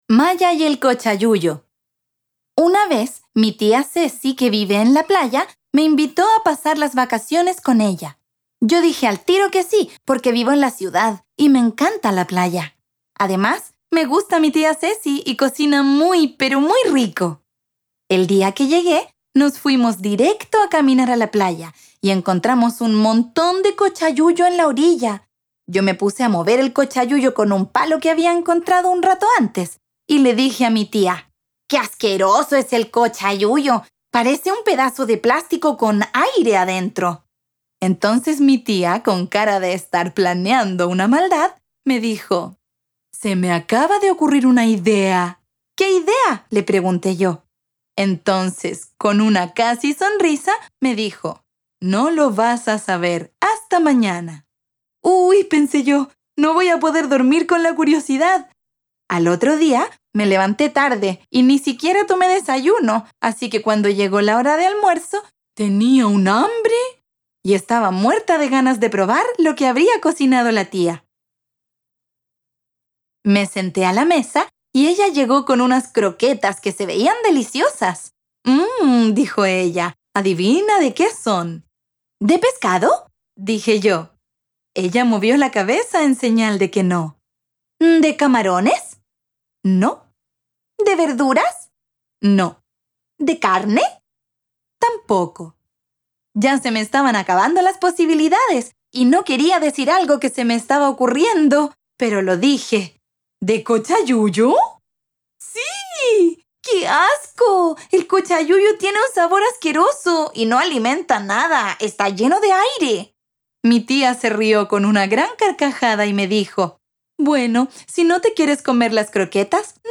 Audiolibro - Extracto Tomo 2